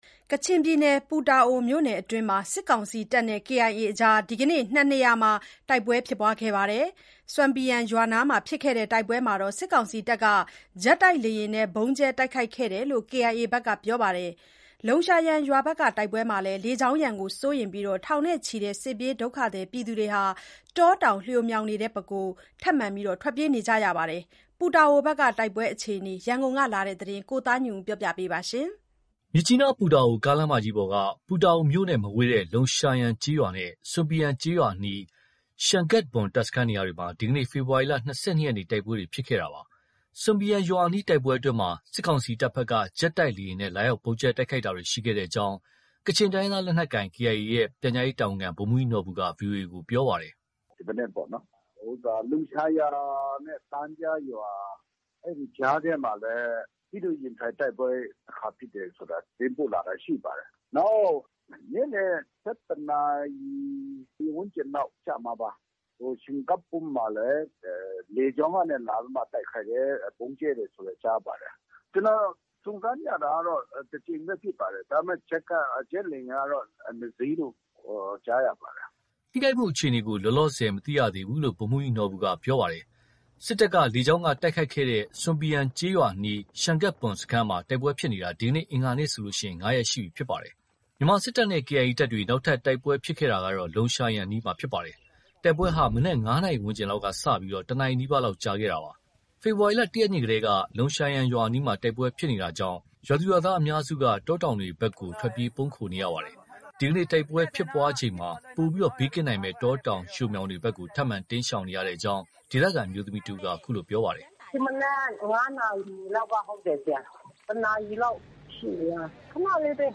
(ဒေသခံအမျိုးမီး)